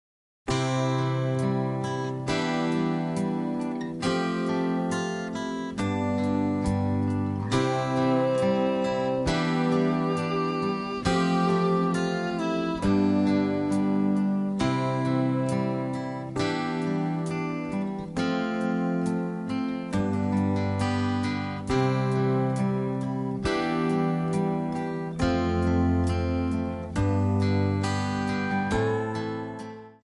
Backing track Karaoke
Country, 1990s